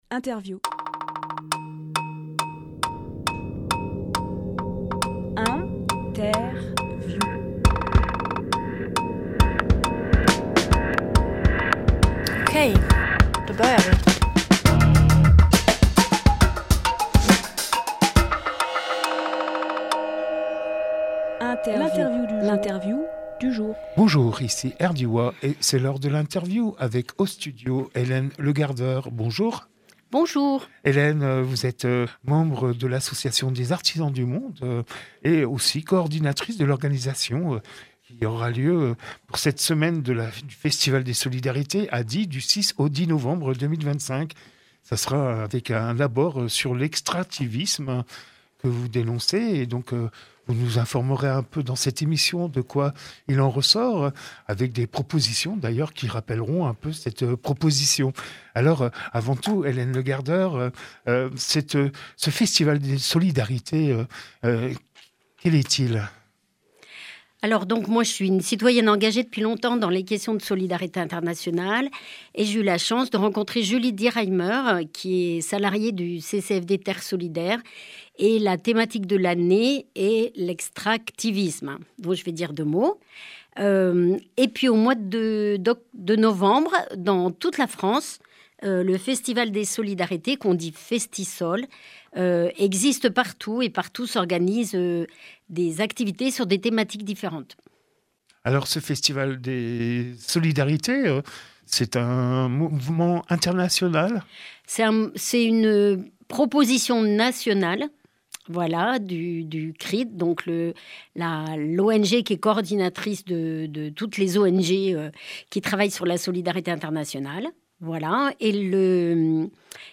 Emission - Interview Festival des Solidarités sur l’extractivisme Publié le 4 novembre 2025 Partager sur…